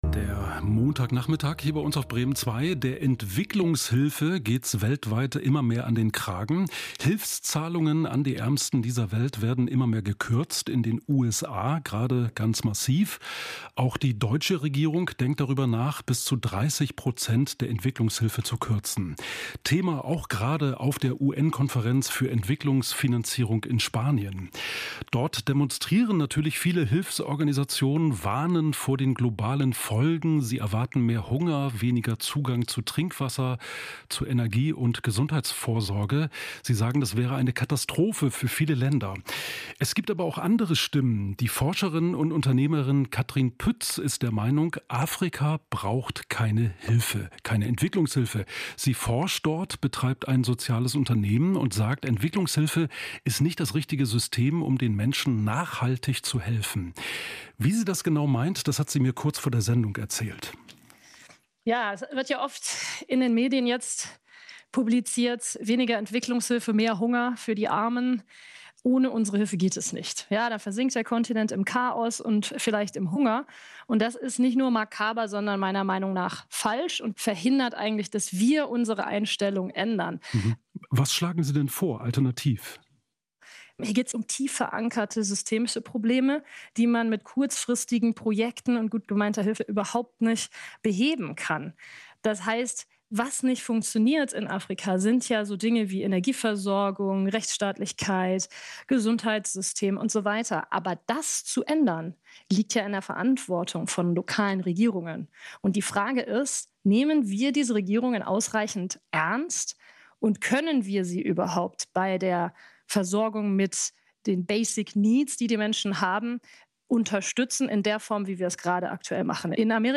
Original Interview